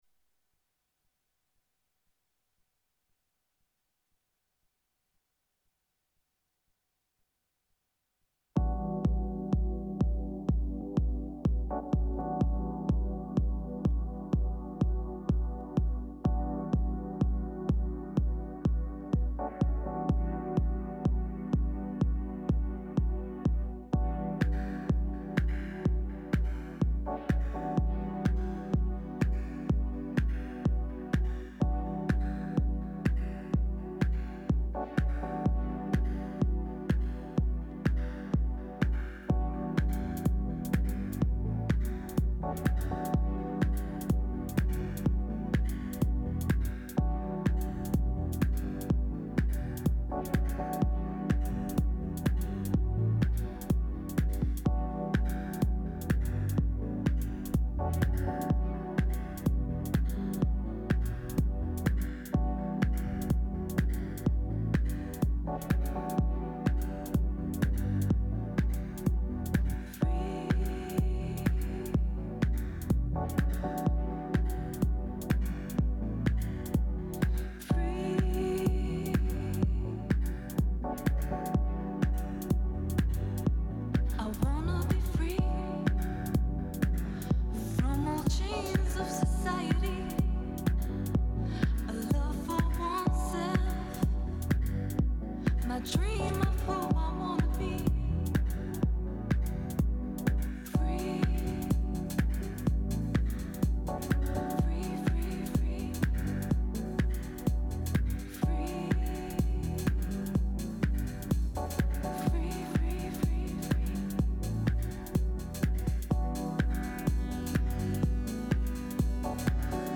This month, it’s a selection of house music….